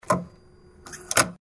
card_insert.mp3